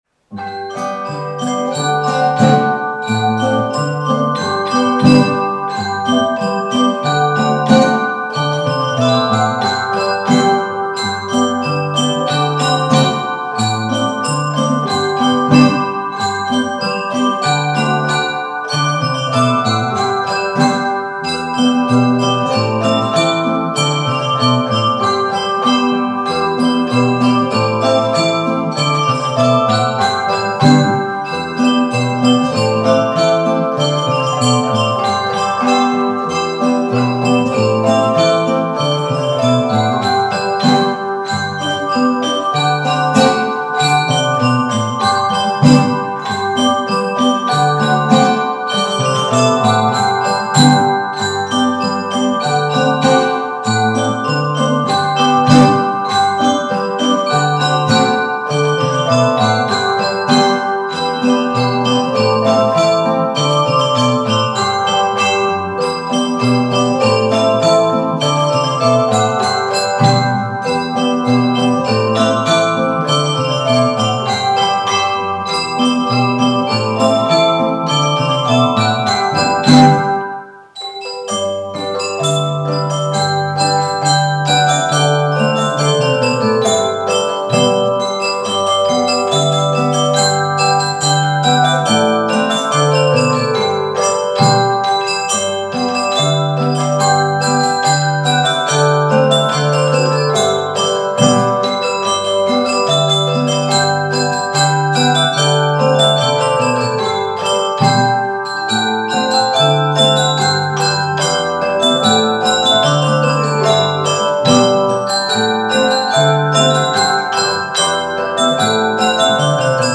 Campanine a Ranica per la rassegna de Gli Zanni ‘Ndèm a cantà
Suonata per campane con dieci campanelle svizzere.
Valzer di Albino eseguito dagli allievi FCB.